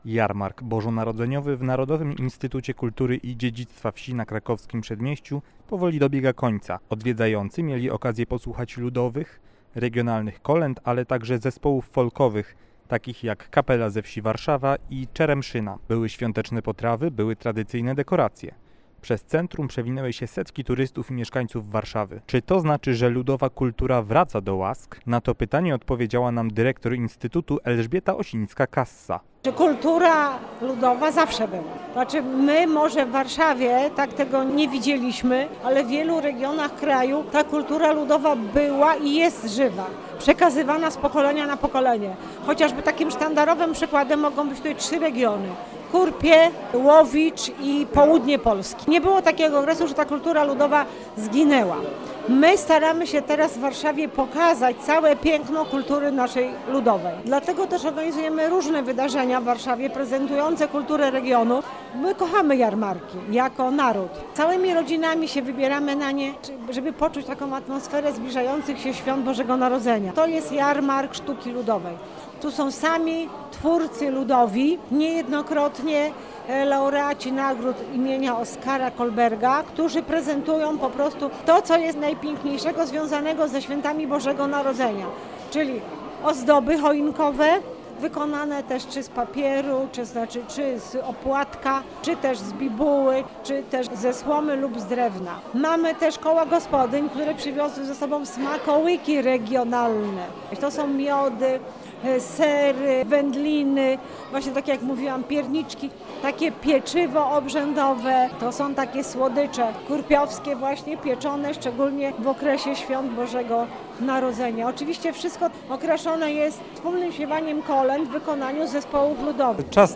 Muzyka, ozdoby świąteczne oraz regionalne potrawy – to główne atrakcje Jarmarku Świątecznego, który odbył się w Narodowym Instytucie Kultury i Dziedzictwa Wsi na Krakowskim Przedmieściu w Warszawie.